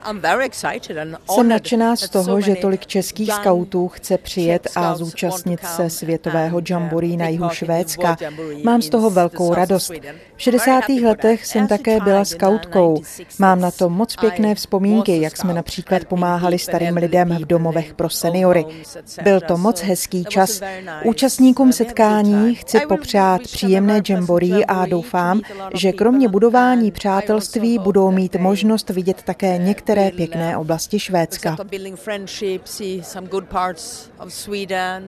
Slavnostního odjezdu se zúčastnila mimo jiné  švédská velvyslankyně v České republice Inger Ultvedtová, která vyjádřila radost (audio WAV)